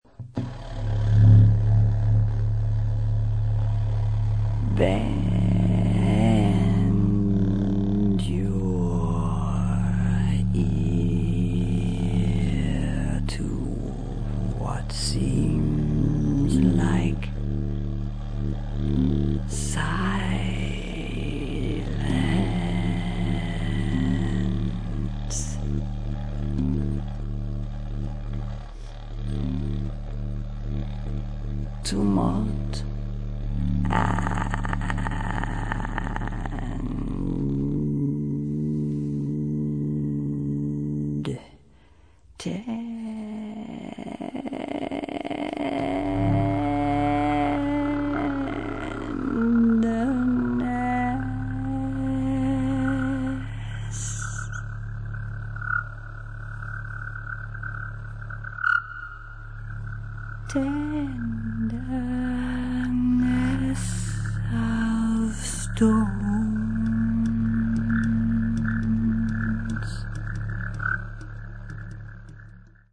saxes & electronics
voice